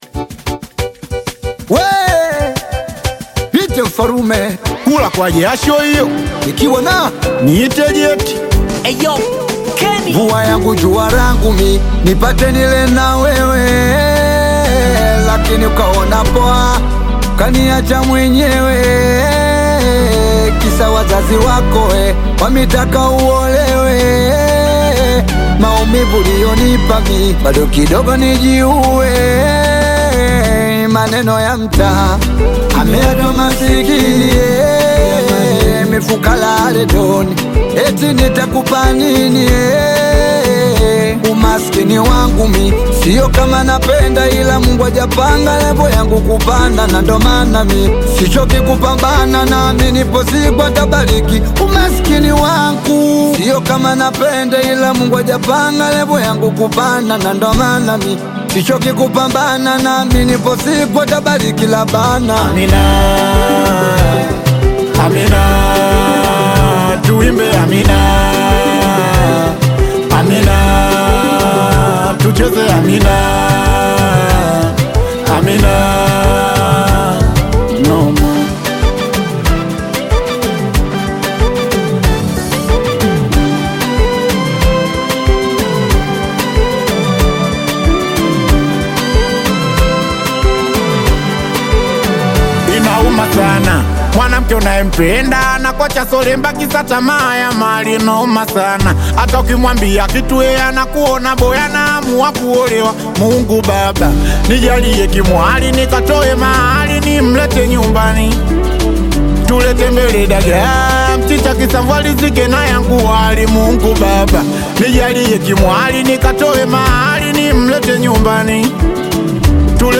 Singeli song